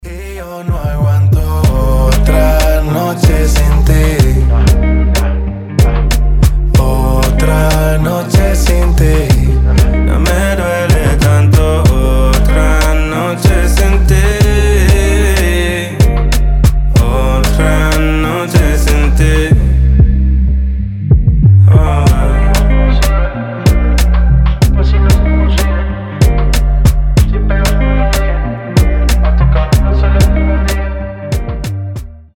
• Качество: 320, Stereo
мужской голос
грустные
dancehall
медленные
латиноамериканские